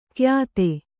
♪ kyāte